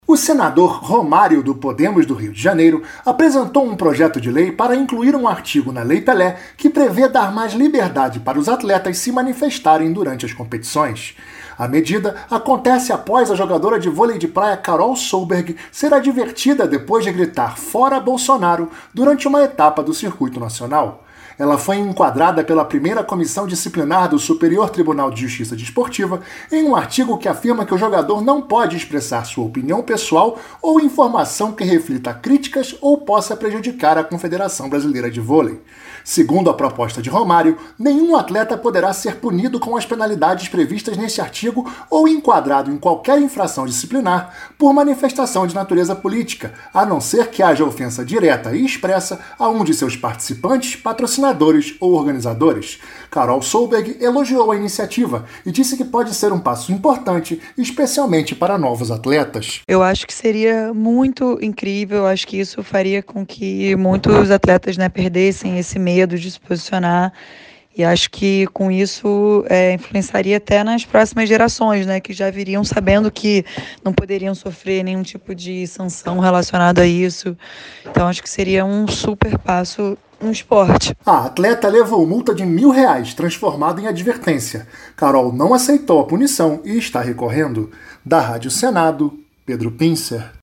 Ela elogiou a iniciativa e disse que pode ser um passo importante para que muitos atletas tenham liberdade de se posicionar. Ouça os detalhes na reportagem